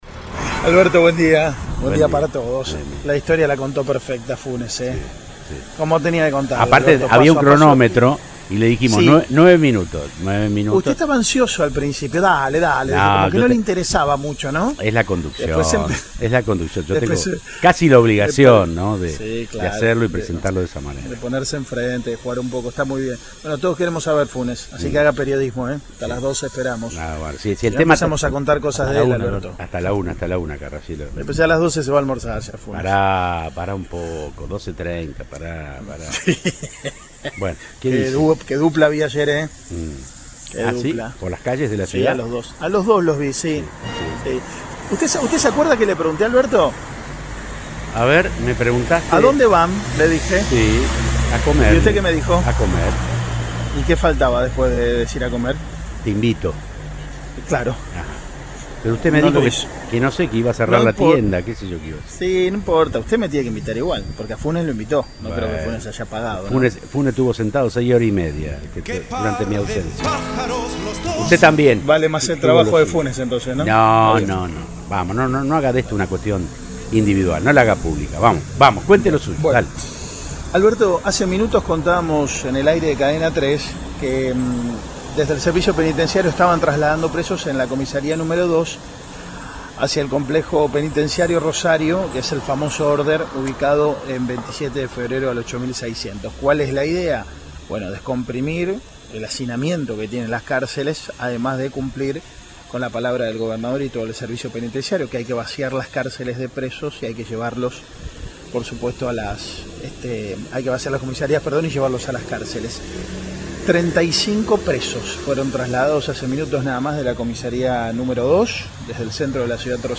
Lucía Masneri, secretaria de Asuntos Penales de Santa Fe, dijo al móvil de Cadena 3 Rosario que todavía "tenemos 300 personas detenidas” en comisarías.